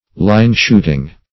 line-shooting - definition of line-shooting - synonyms, pronunciation, spelling from Free Dictionary